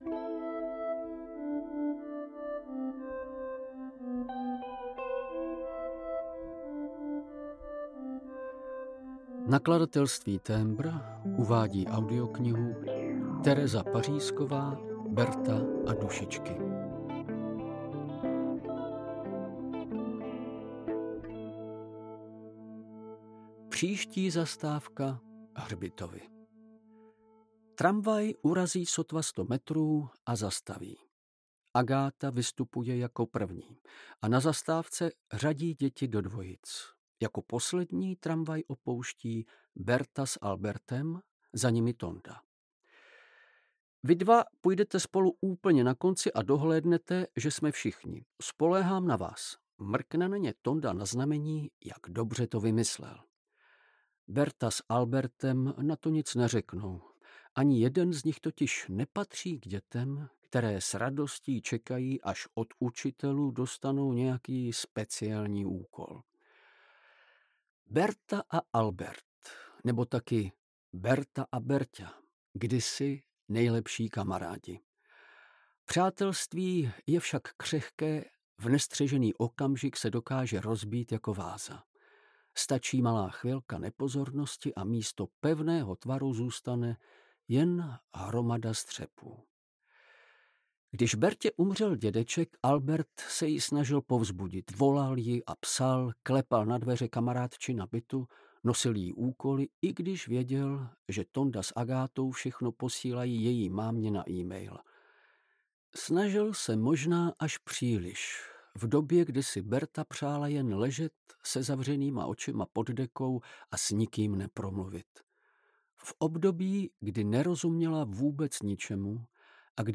Audio knihaBerta a Dušičky
Ukázka z knihy
• InterpretVladimír Javorský